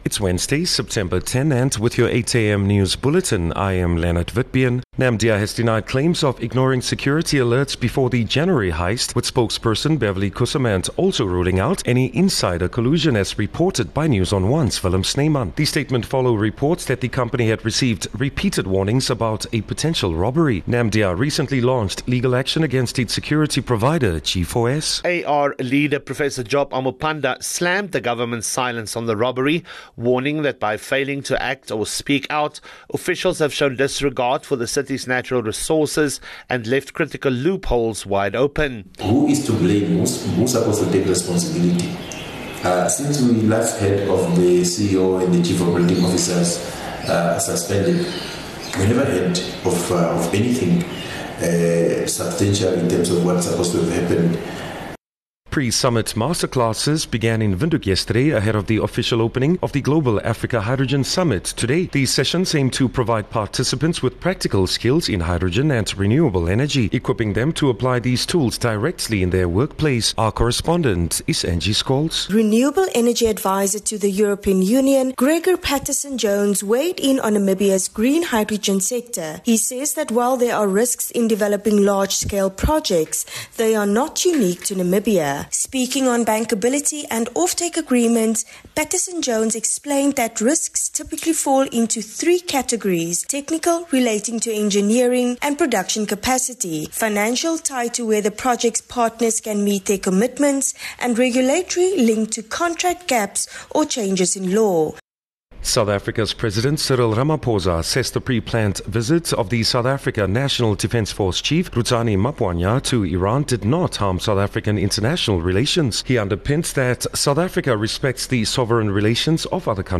10 Sep 10 September-8am news